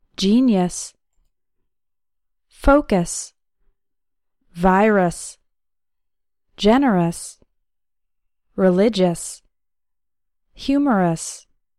Note that both spellings are still pronounced -us.
Listen to the audio and note how both -us and -ous endings are pronounced -us.